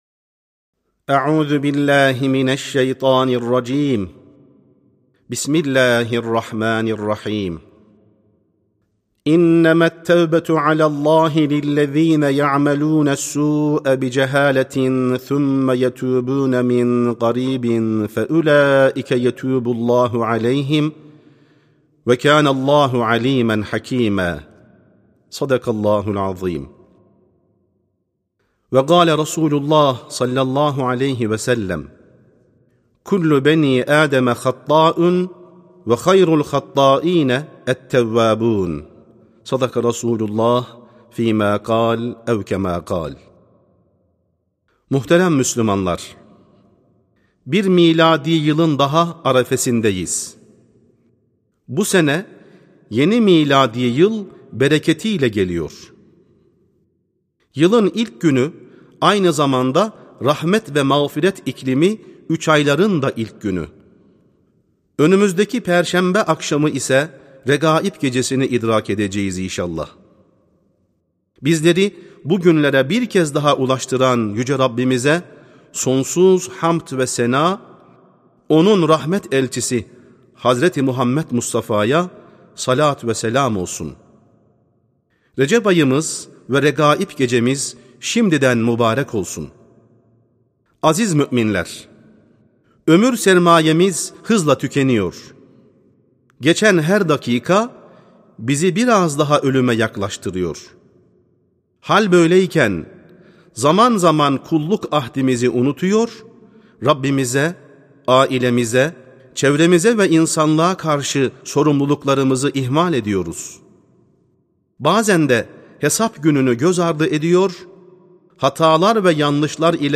Sesli Hutbe (Hata Yapanların En Hayırlısı, Hatasına Tövbe Edendir).mp3